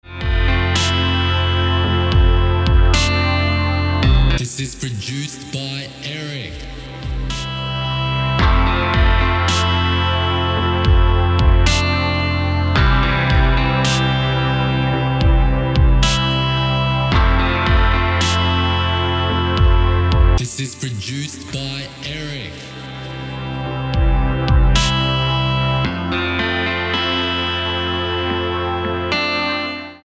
Featuring an iconic electric guitar.
Key: F Major Tempo: 110BPM Time: 4/4 Length: 3:47